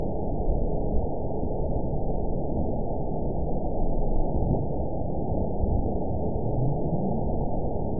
event 920527 date 03/28/24 time 21:01:36 GMT (1 year, 1 month ago) score 9.47 location TSS-AB04 detected by nrw target species NRW annotations +NRW Spectrogram: Frequency (kHz) vs. Time (s) audio not available .wav